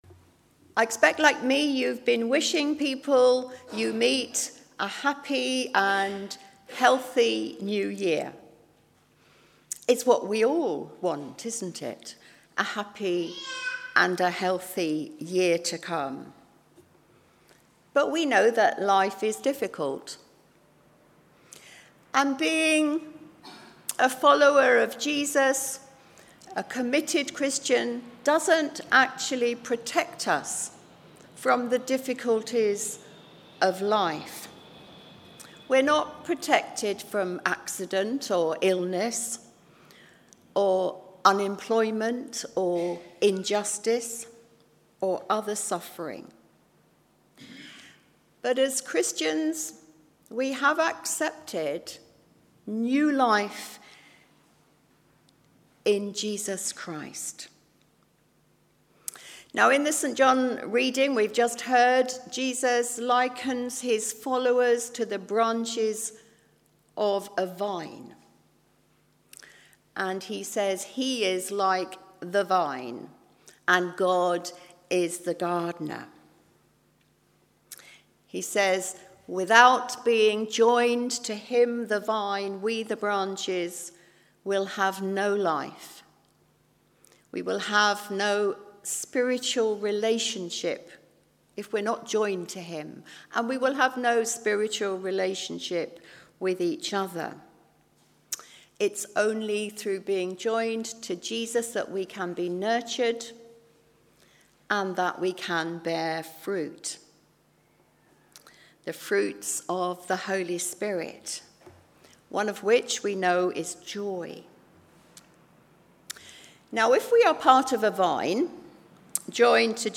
“I am the vine” – Covenant Service